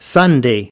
8.Sunday  /ˈsʌn.deɪ/ : chủ nhật